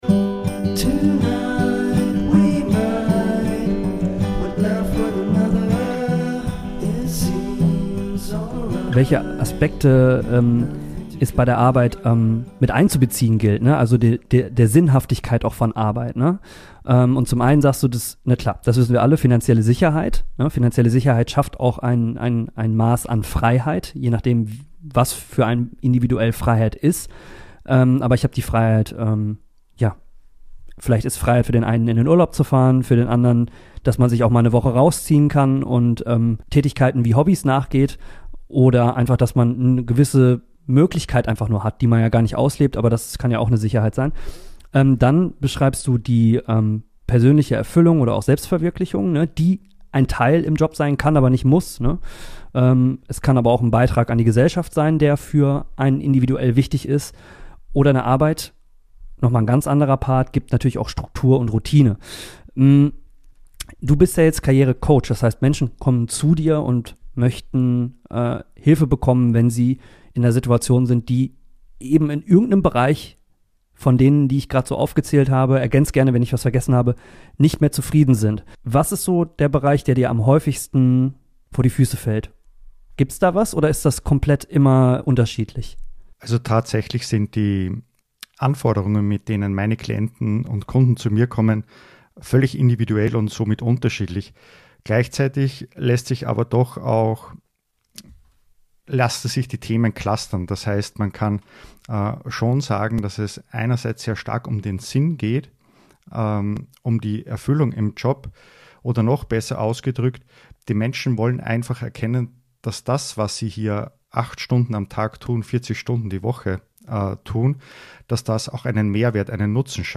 In dieser kompakten Spezialfolge hörst du ausgewählte Impulse aus bereits geführten Interviews über Sinn, Purpose und gelebte Lebensaufgaben.